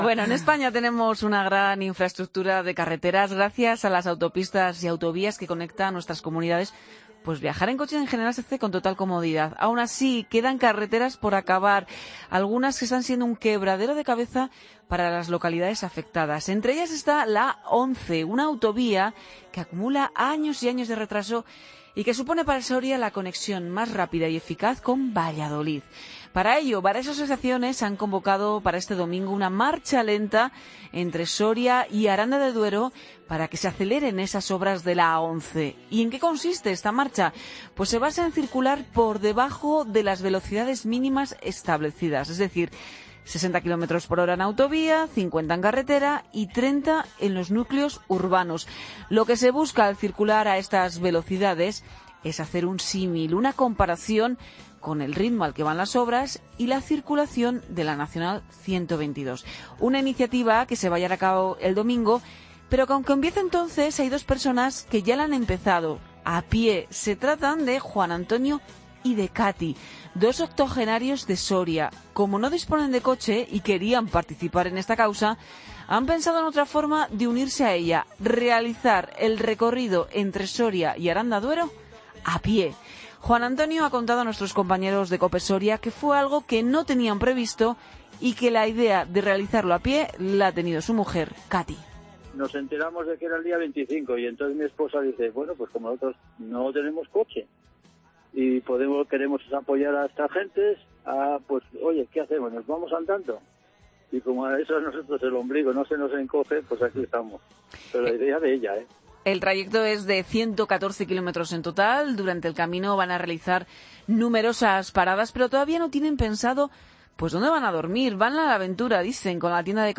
[REPORTAJE] La cadena COPE se hace eco a nivel nacional de la Marcha lenta convocada por Soria ¡YA!